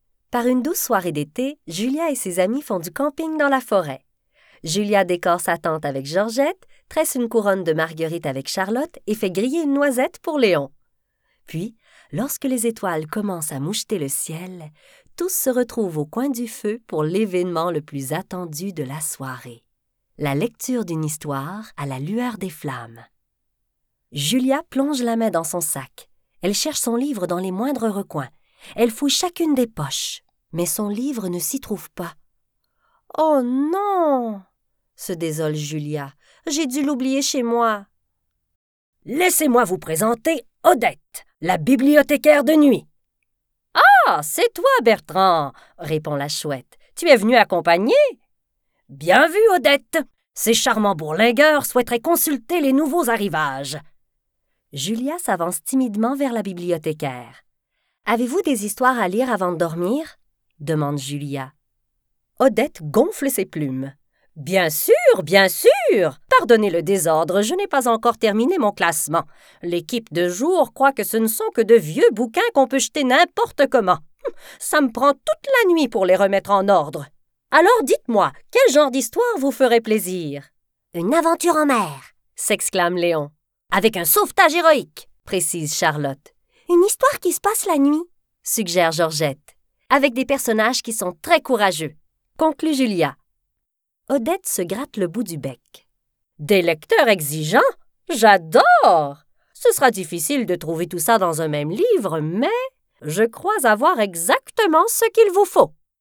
livre audio jeunesse – avec voix de personnages